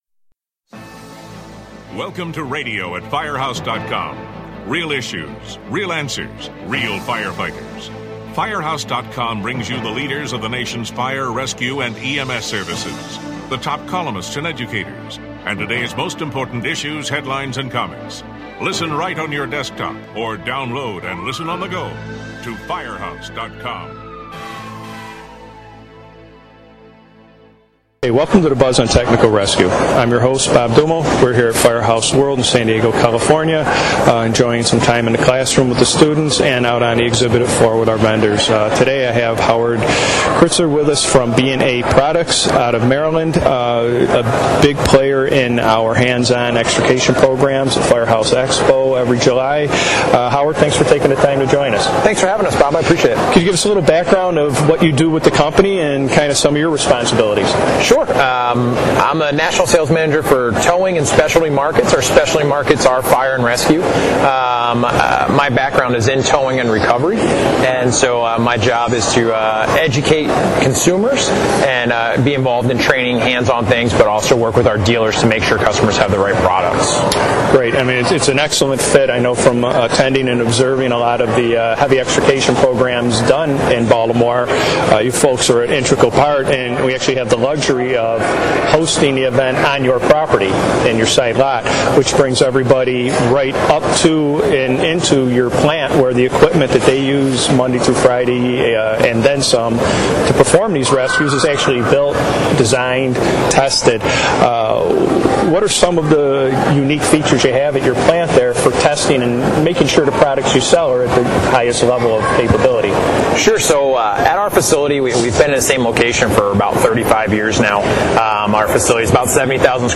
They talk about the interface between first responders and the towing industry when working at various extrication and heavy rigging incidents.